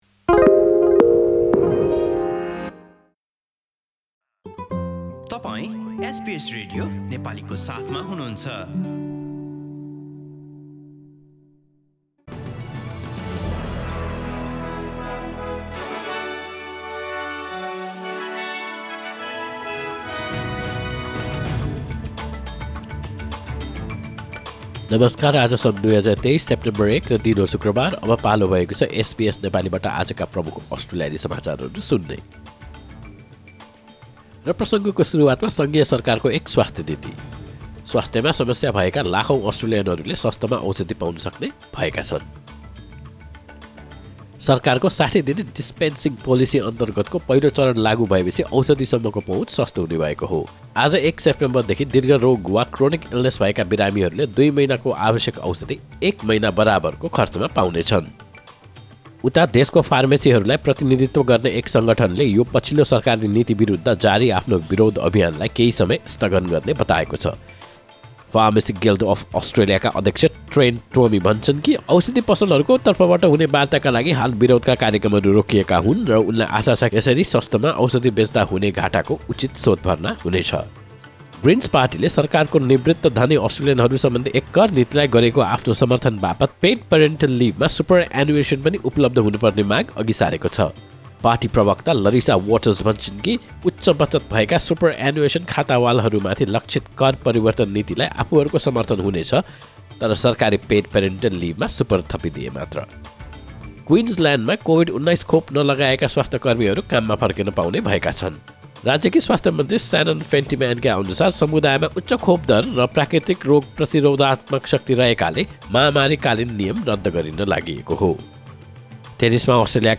एसबीएस नेपाली प्रमुख अस्ट्रेलियाली समाचार: शुक्रवार, १ सेप्टेम्बर २०२३